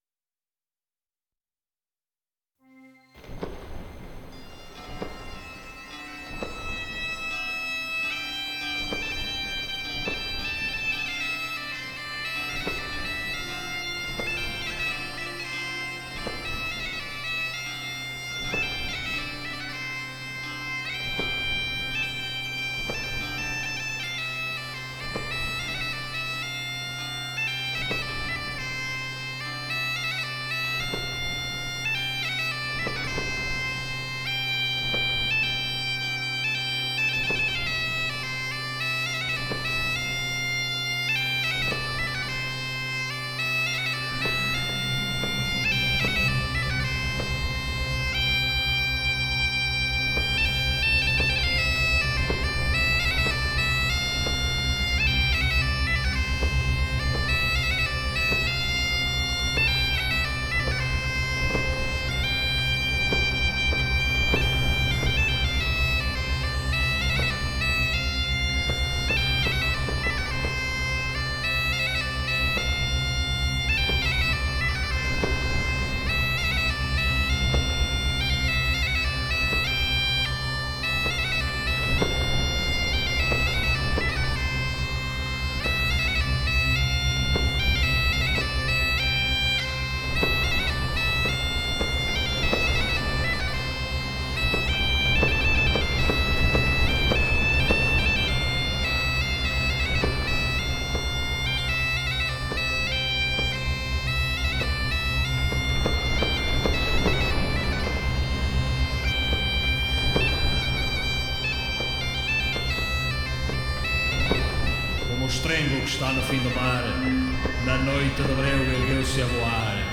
Rapsódia